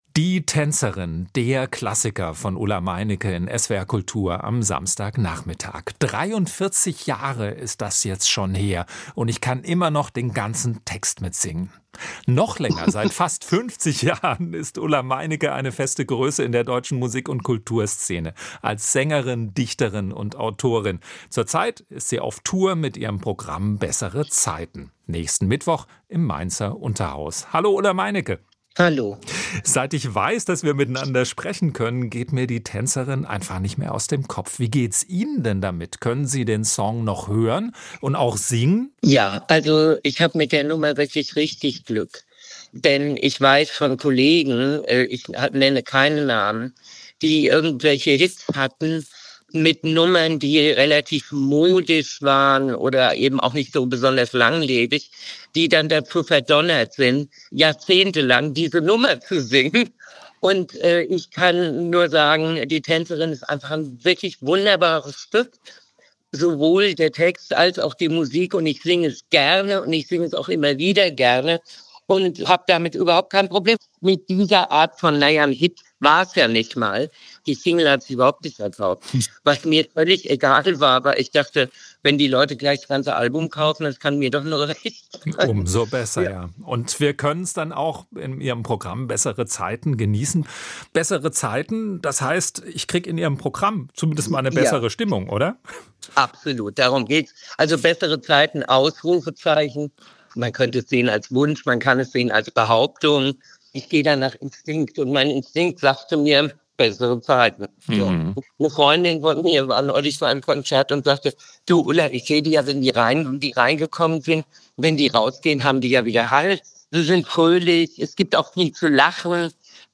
Die Dichterin, Autorin und Sängerin Ulla Meinecke besticht durch ihre signifikante, leicht rauchige Stimme und treffgenaue Wortwahl. Mark Twain sei ihr großes dichterisches Idol, erzählt sie.